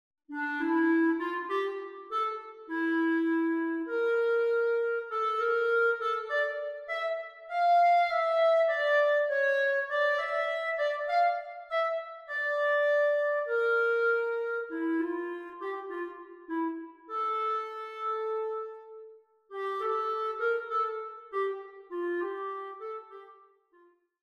A short extract from